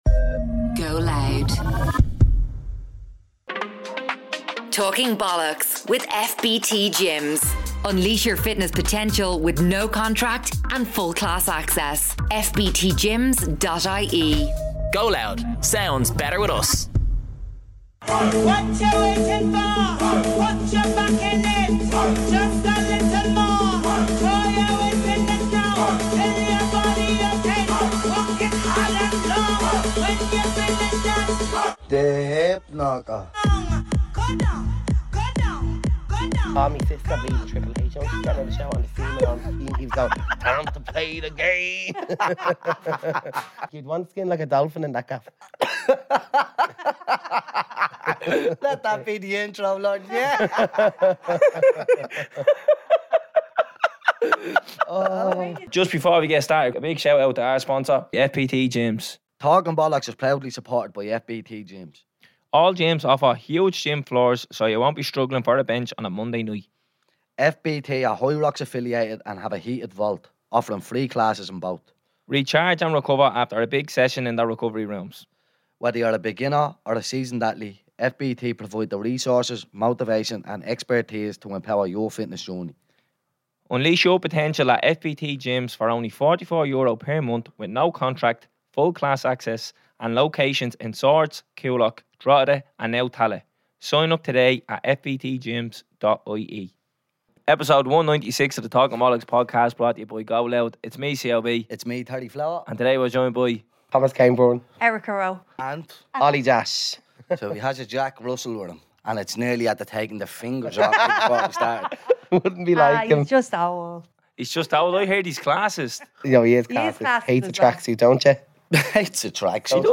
Two lads from the inner city of Dublin sitting around doing what they do best, talking bollox.